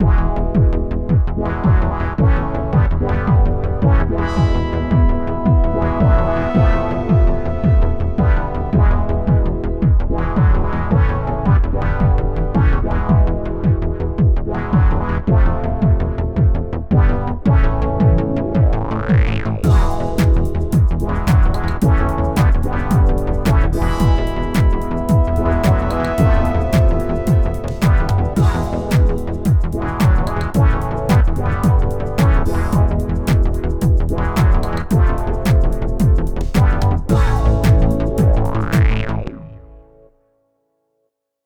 And I made a quick test using it for all tracks (including the kick drum, but not the rest of the percussion).